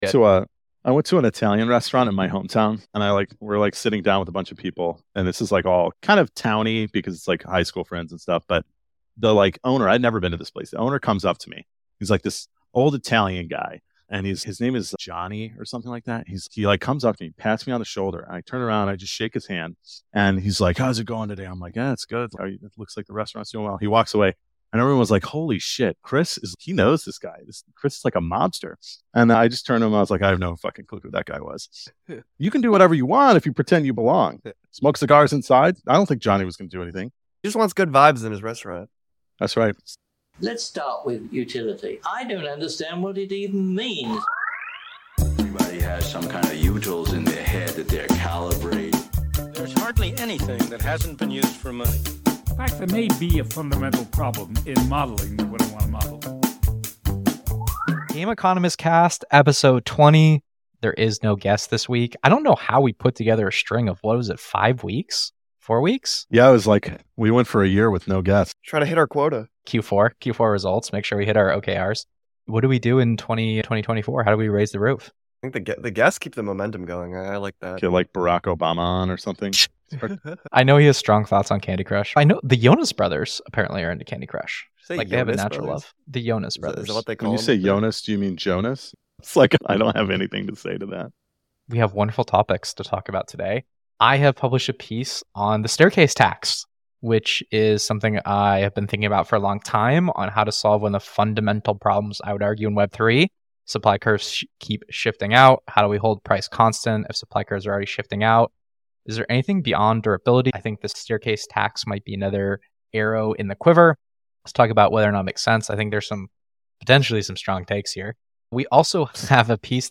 The crew debates the meaning of game inflation: in what units and for whom? The answer is not as clear as first thought.